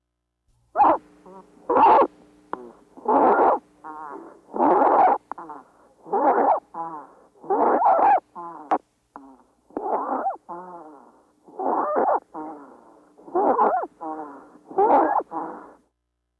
Stridor is a coarse, high-pitched sound heard during inspiration.
Stridor Voice clip
infant-stridor.wav